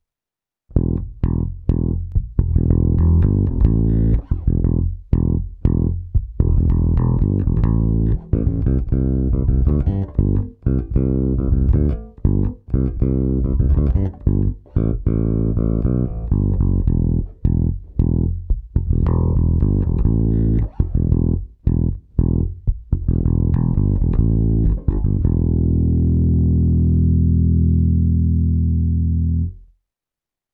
Nahrávku jsem prohnal multiefektem Hotone Ampero, ale v něm mám zapnutou prakticky jen drobnou ekvalizaci a hlavně kompresor.
Kobylkový snímač - basy +100%, středy +50%, výšky centr